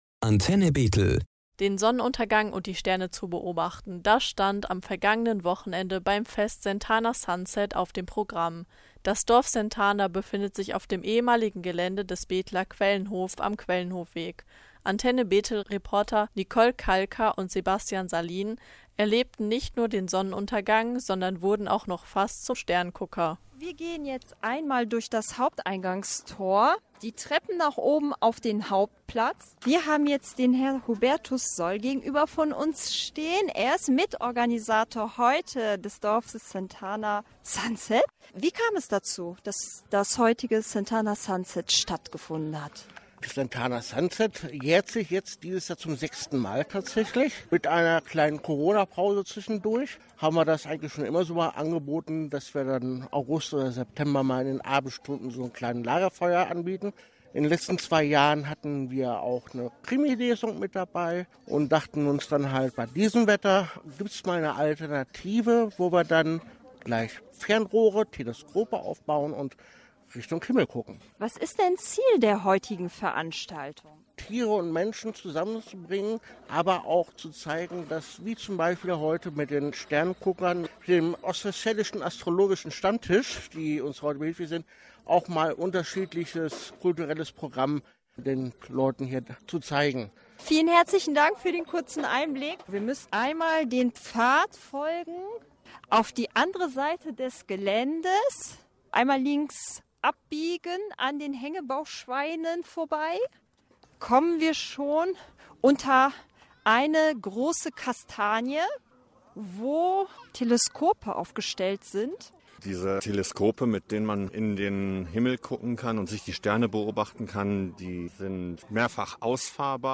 Mit dem sogenannten „Sunset“ wird im Dorf Sentana, dem Gnadenhof für alte oder nicht gewollte Tiere der Herbst begrüßt. Antenne Bethel war hier am vergangenen Wochenden dabei: